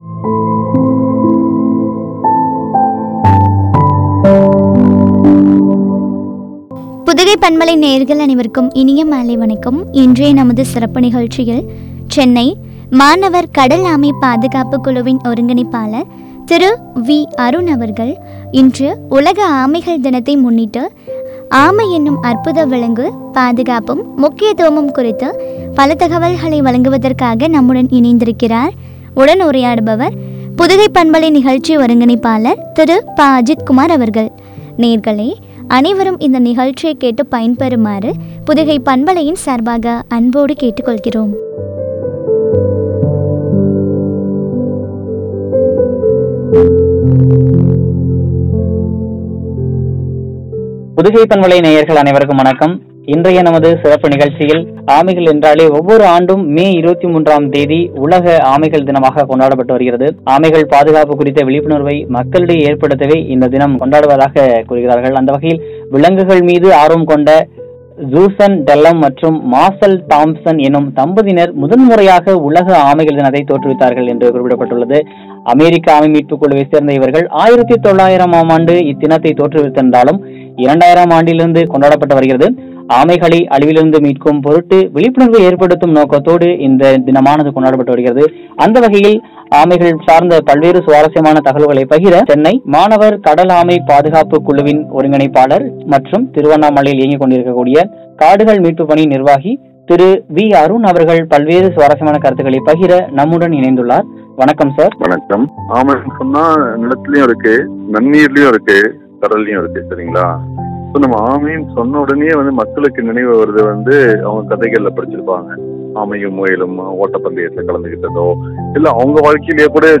முக்கியத்துவமும் பற்றிய உரையாடல்.